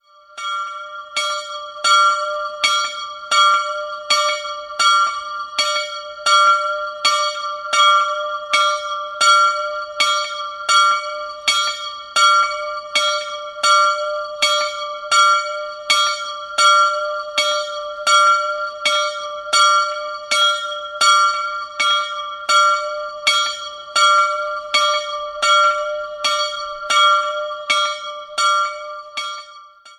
Die Glocke der Kapelle St. Maria in Unterbürg
Klicken Sie hier, um das Geläut anzuhören: